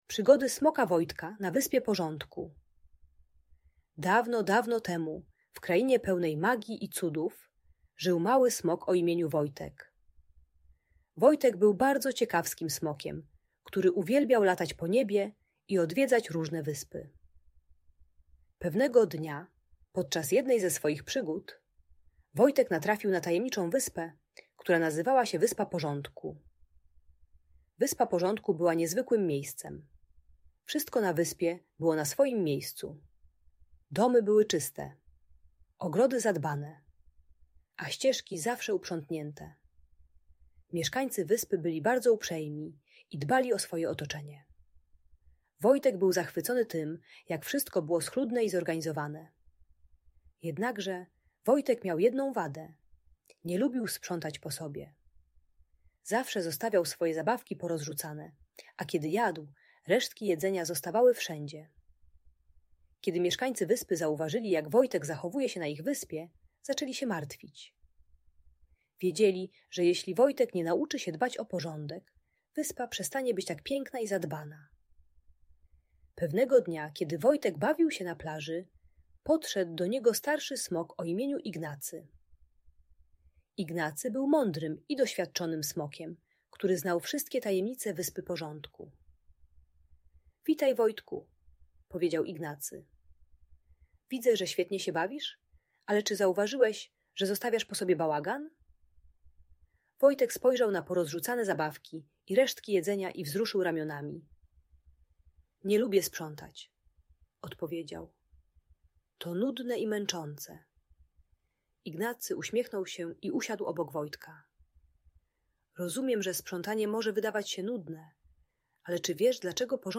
Przygody Smoka Wojtka - Agresja do rodziców | Audiobajka